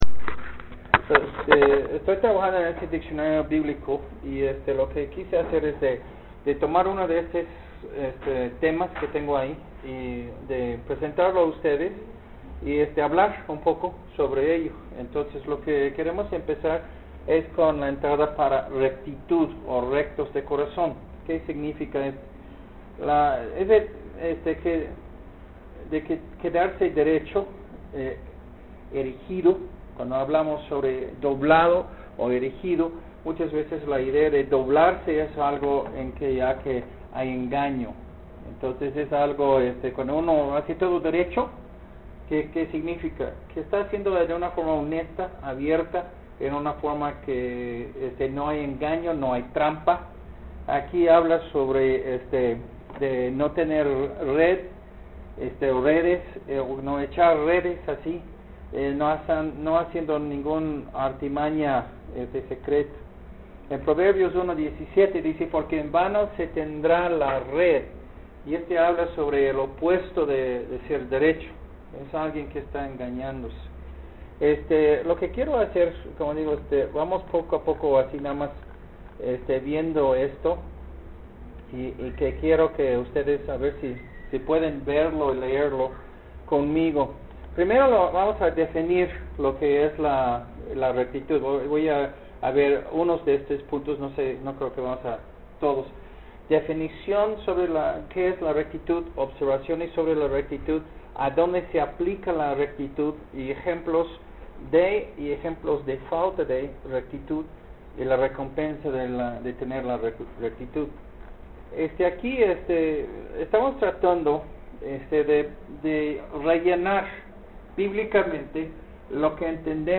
Rectitud Sermón en Audio (Apuntes)